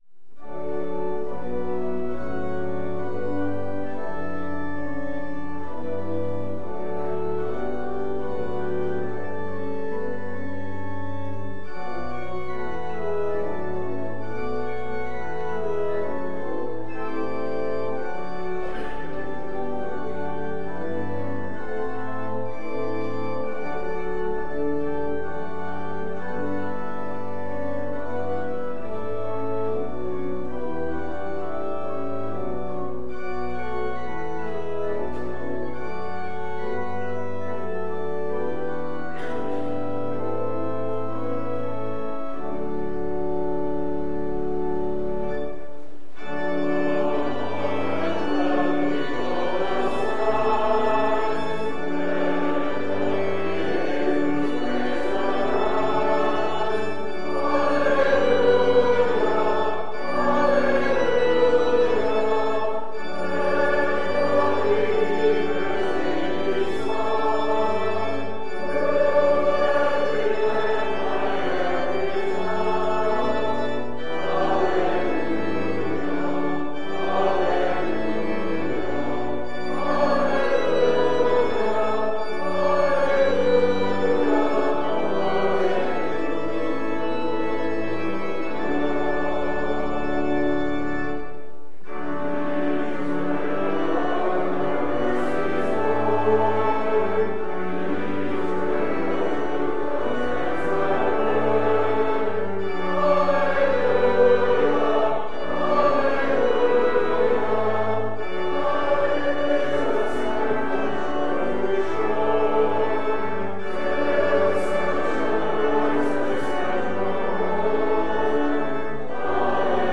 LCOS Worship Service